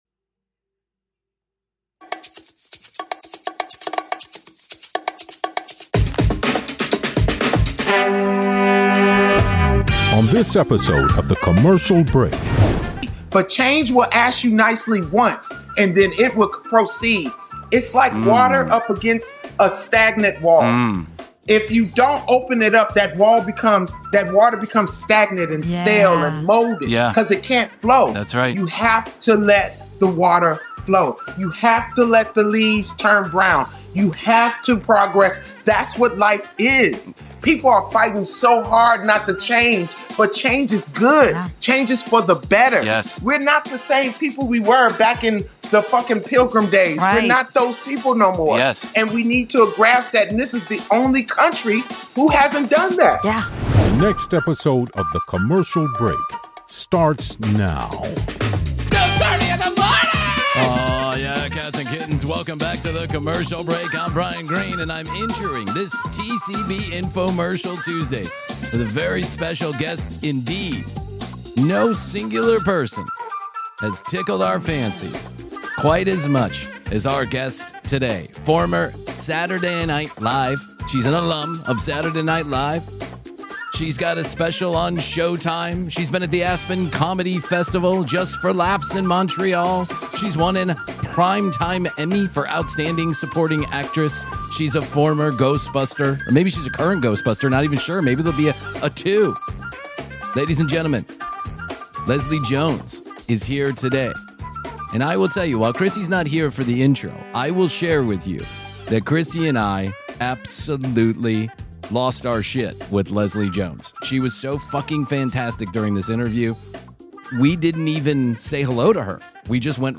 Leslie brings her trademark honesty, fire, and razor-sharp wit as the co. Podcast links by Plink.